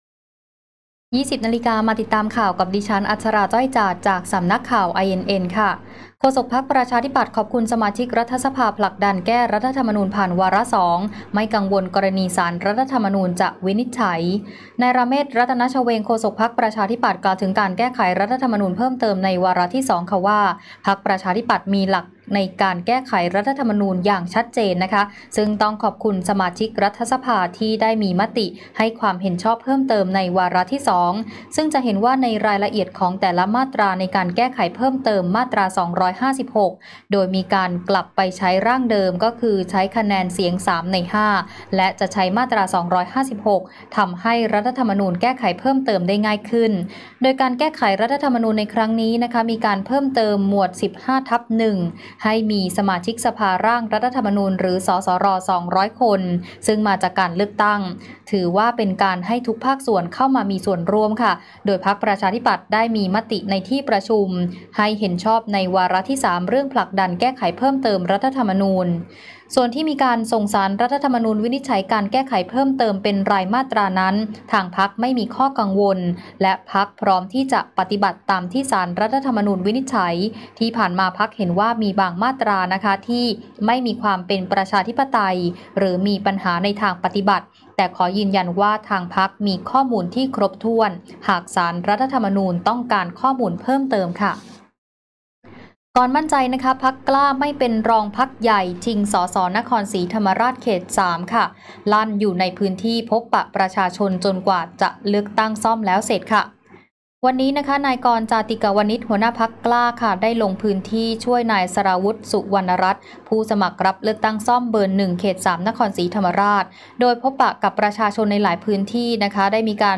ข่าวต้นชั่วโมง 20.00 น.